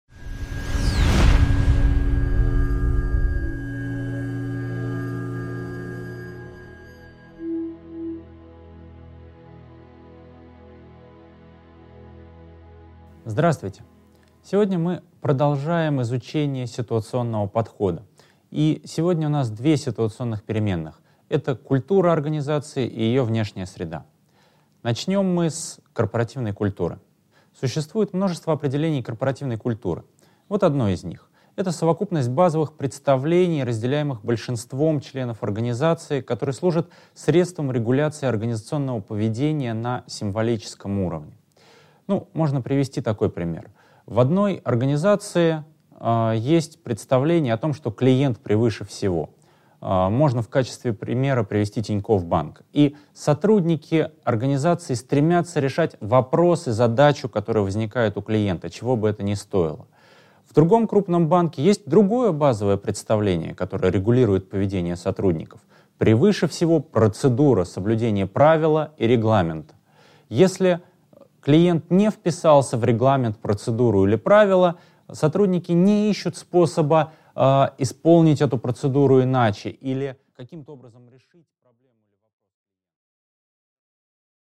Аудиокнига 6.1. Культура организации и структура | Библиотека аудиокниг